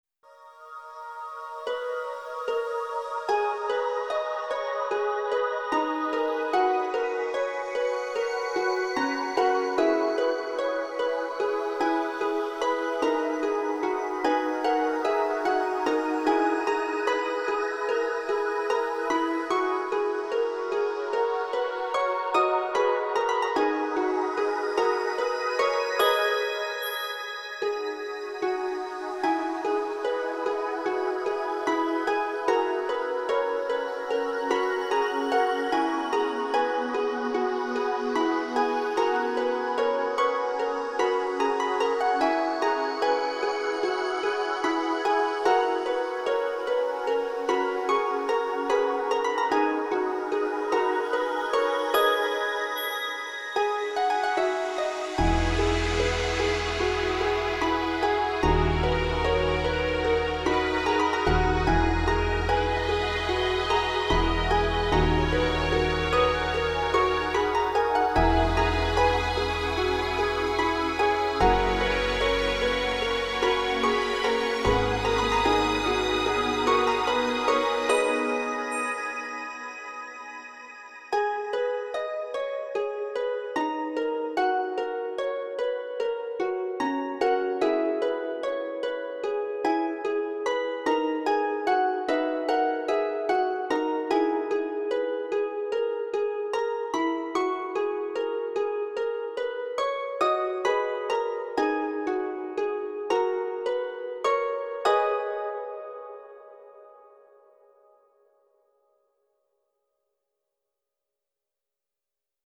In the key of B, this piece has been a good exercise in learning all the capabilities of Sonar.